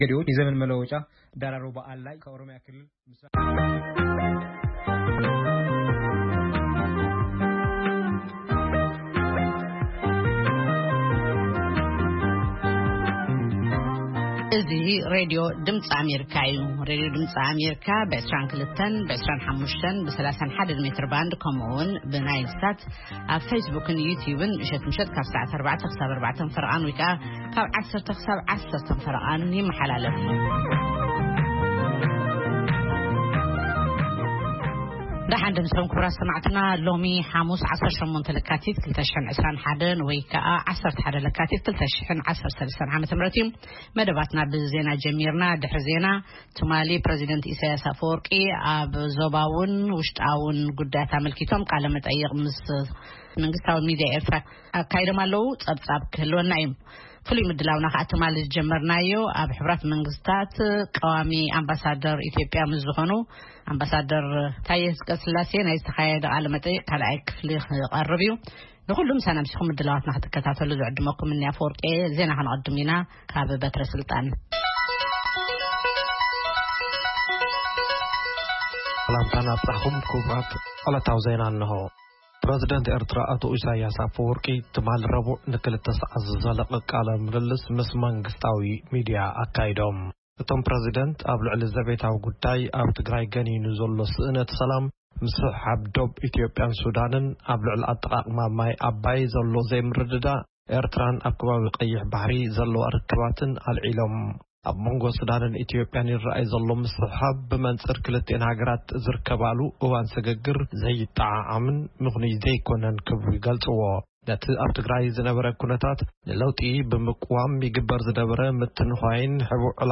ፈነወ ትግርኛ ብናይ`ዚ መዓልቲ ዓበይቲ ዜና ይጅምር ። ካብ ኤርትራን ኢትዮጵያን ዝረኽቦም ቃለ-መጠይቓትን ሰሙናዊ መደባትን ድማ የስዕብ ። ሰሙናዊ መደባት ሓሙስ፡ መንእሰያት/ ጥዕና